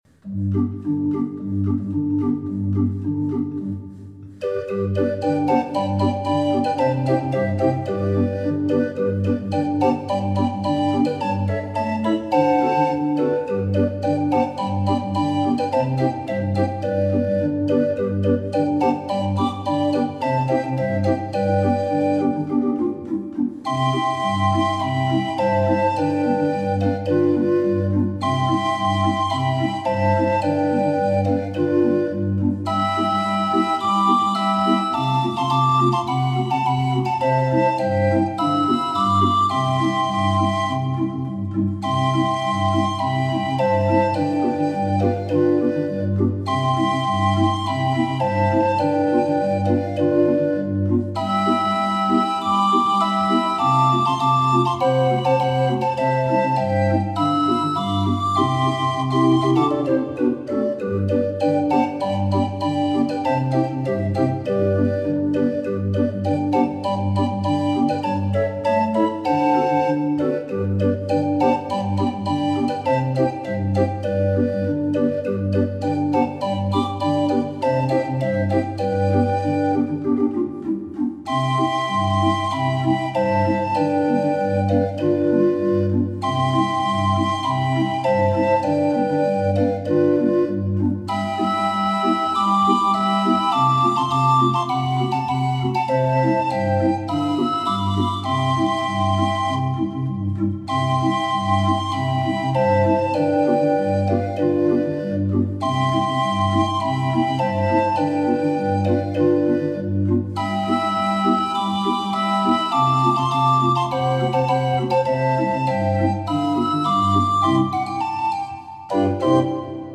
26 street organ